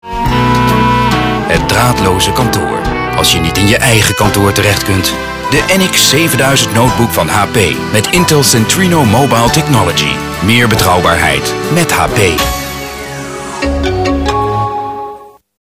Native Dutch speaker, voice over Eurosport, warm strong voice
Sprechprobe: Werbung (Muttersprache):